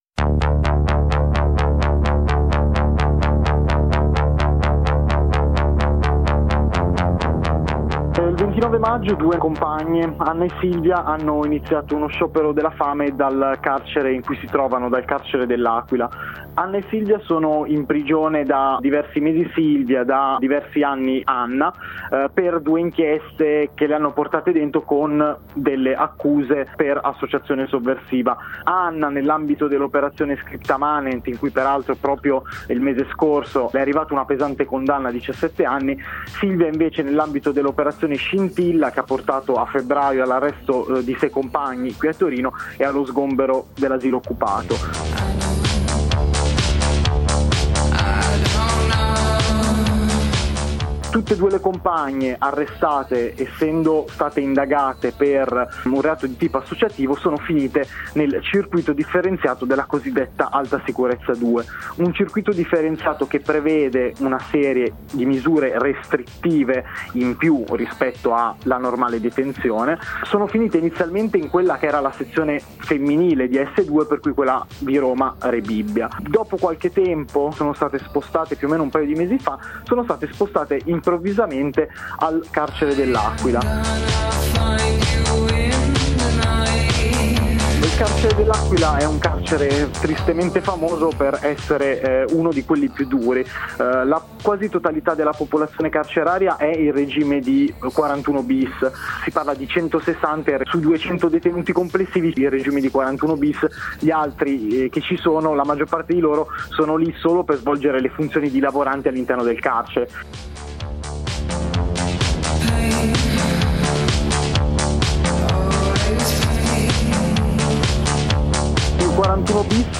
Di tutto ciò ci parla un compagno di Torino.